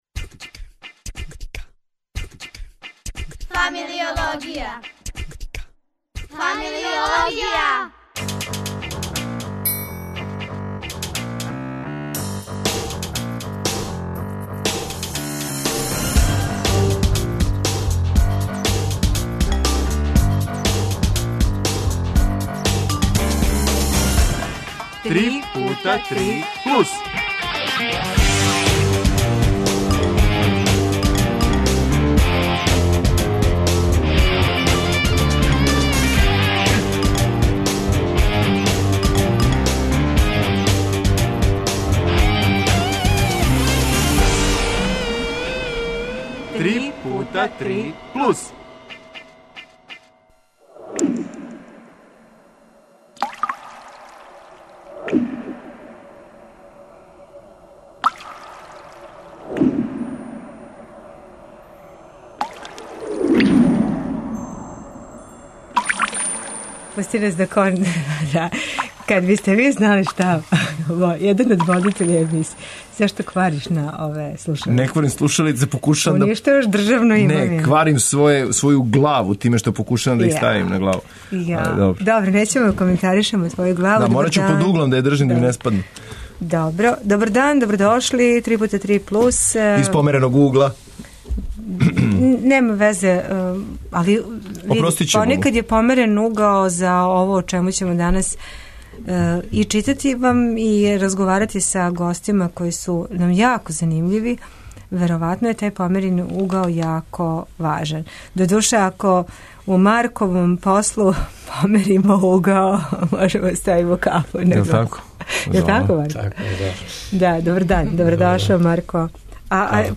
У студију ће бити фризер и представник Циркусфере...